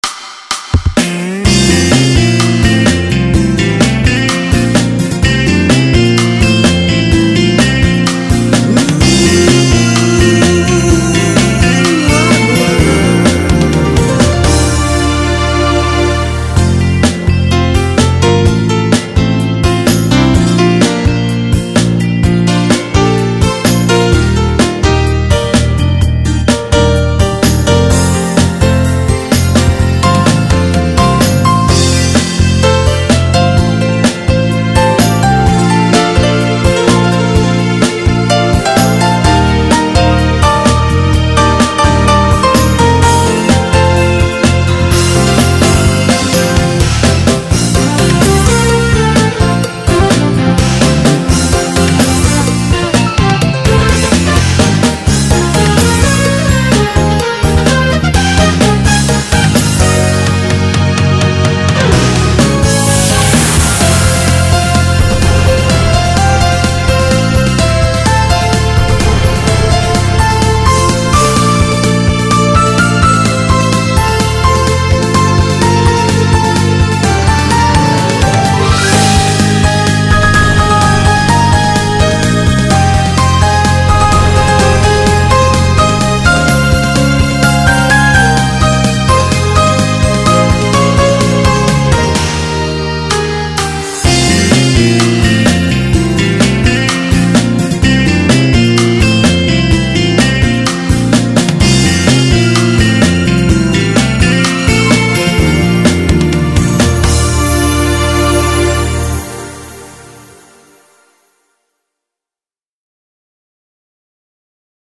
음악들 올려봅니다 게임 O.S.T 에서 나오기 힘든 FUSION 및 JAZZ 장르 몇곡 올려보겠습니다.